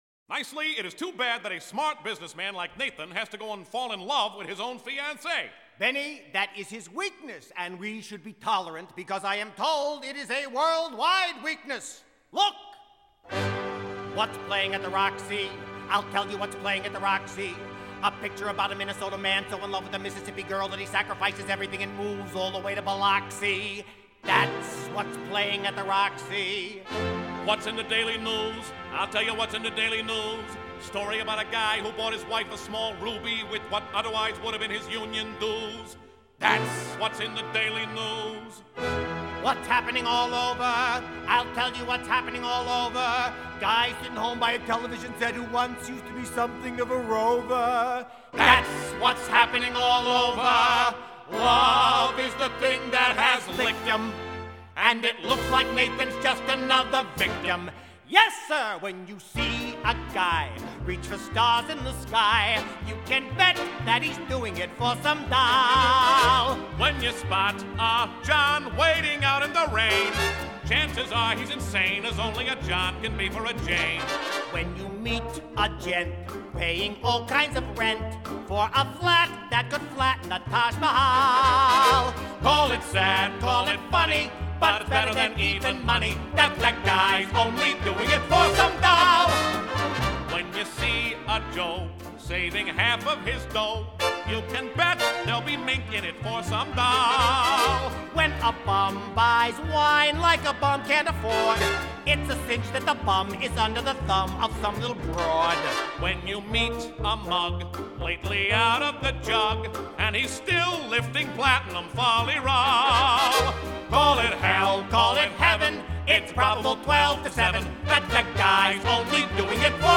It is a fun, amusing song.